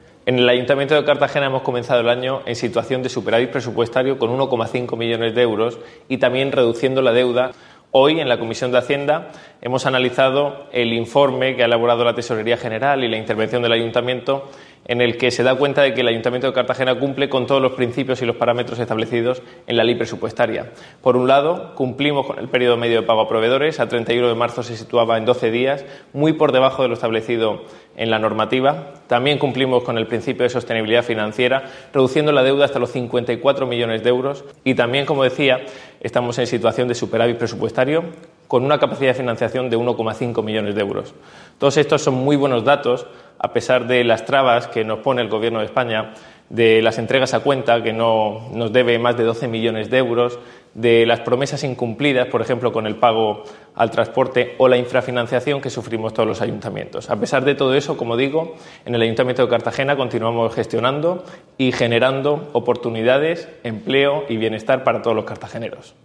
Enlace a Declaraciones del concejal de Hacienda, Ignacio Jáudenes, sobre el informe de la tesorería e Intervención General